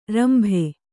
♪ rambhe